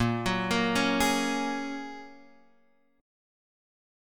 A#m7#5 chord